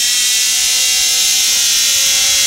Electric Motor Whine
A high-pitched electric motor accelerating with characteristic EV whine and inverter noise
electric-motor-whine.mp3